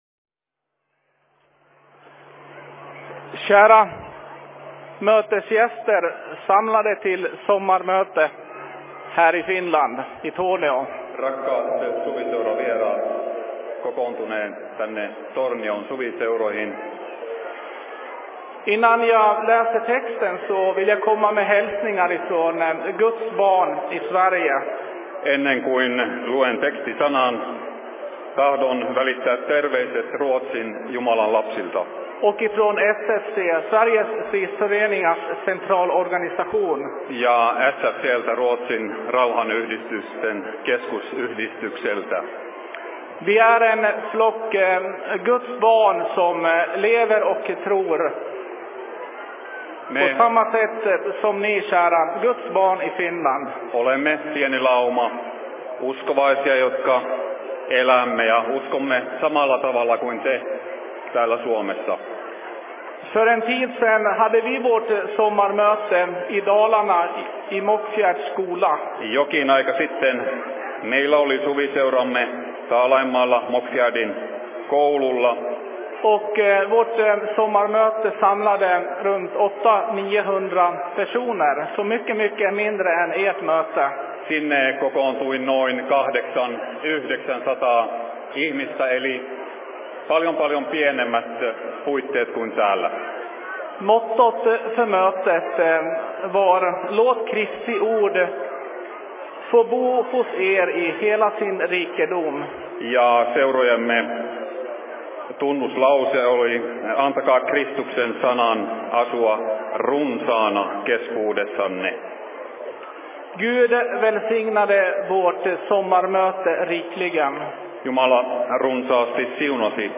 Se Fi Seurapuhe 01.07.2016
Paikka: 2016 Suviseurat Torniossa
Simultaanitulkattu Ruotsi, Suomi